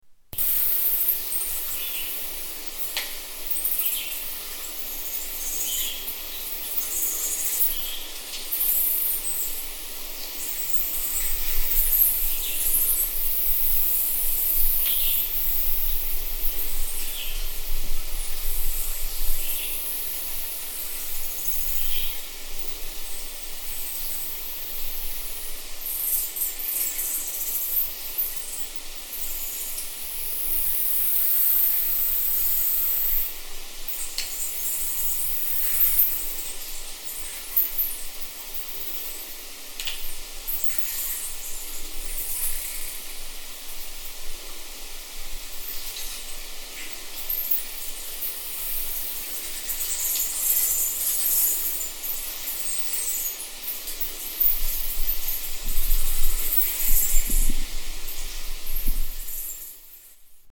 Batcave sounds at Monte Alegre